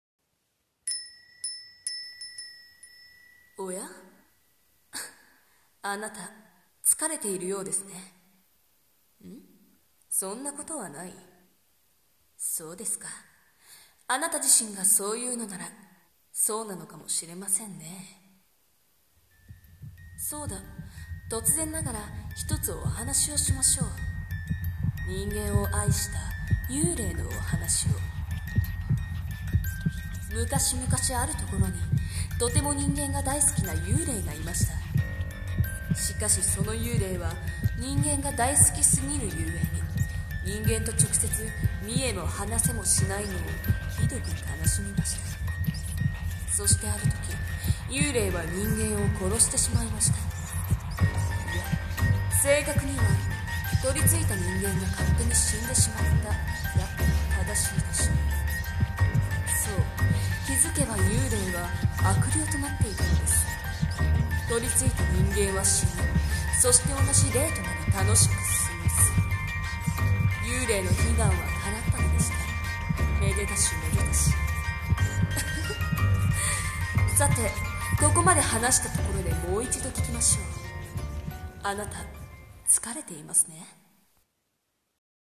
【声劇】あなた、つかれていますね？【ホラー】